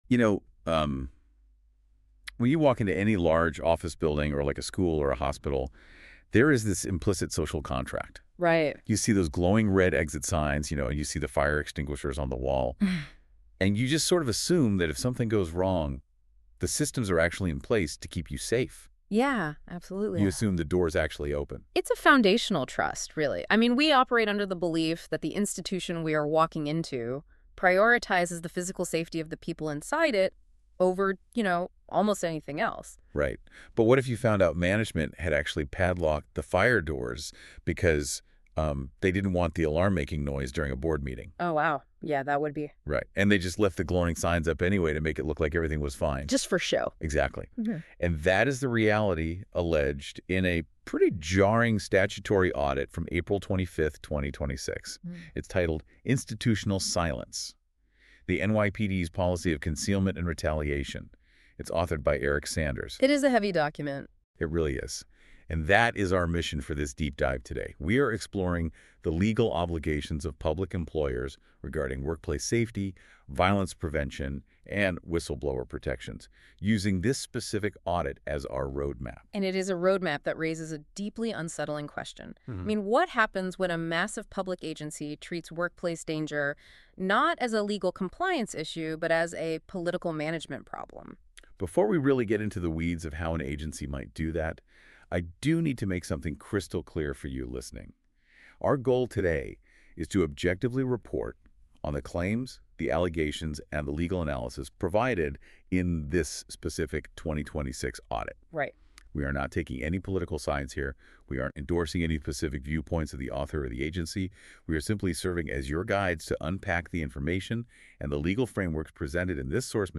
Deep-Dive Audio and Slide-Deck Supplement: How the NYPD Hides Workplace Violence / Institutional Silence Inside the NYPD